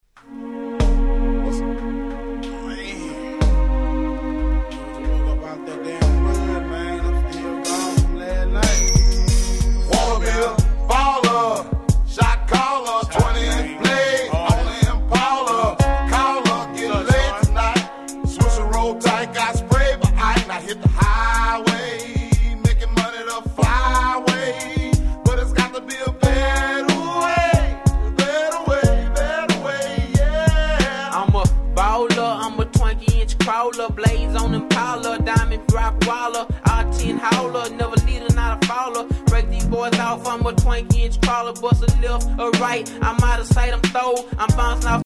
99' Southern Hip Hop Classics !!